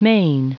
Prononciation du mot main en anglais (fichier audio)
Prononciation du mot : main